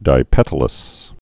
(dī-pĕtl-əs)